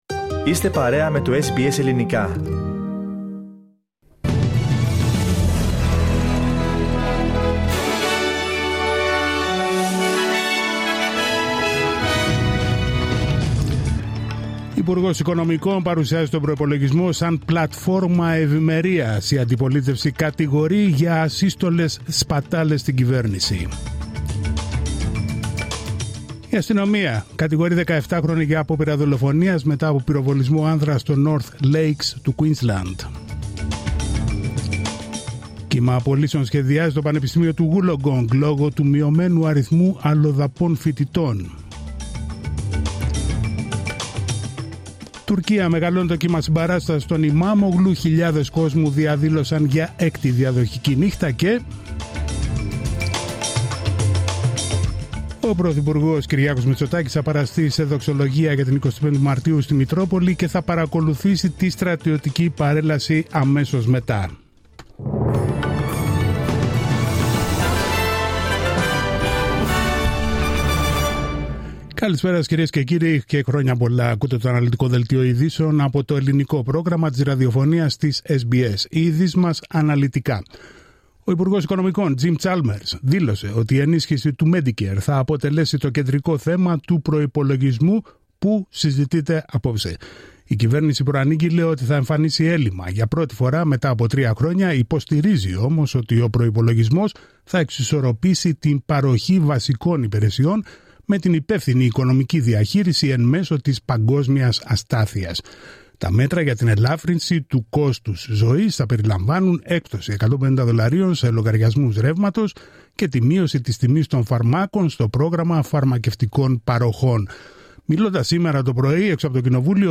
Δελτίο ειδήσεων Τρίτη 25 Μαρτίου 2025